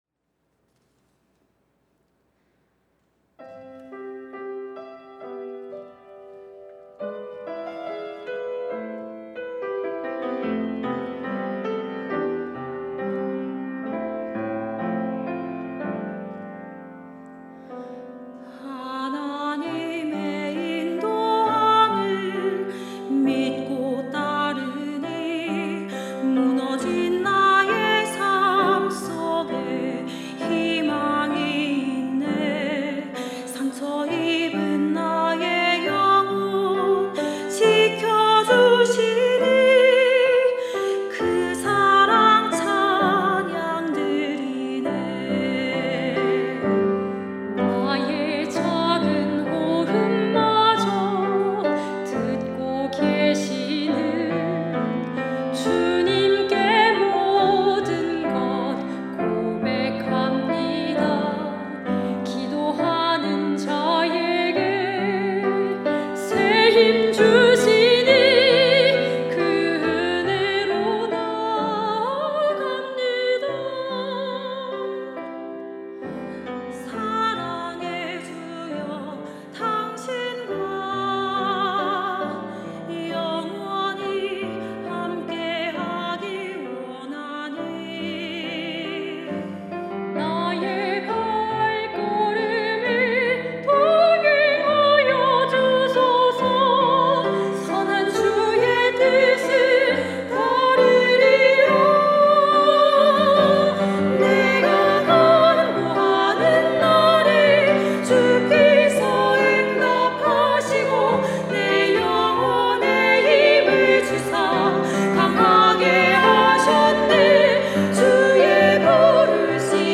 특송과 특주 - 내가 간구하는 날에